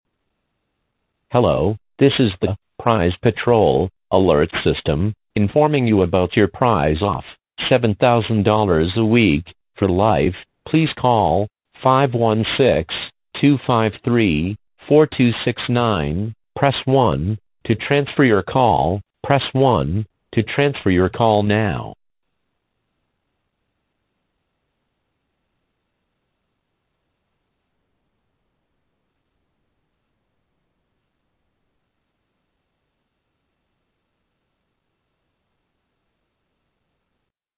Voicemail
Robocall :arrow_down: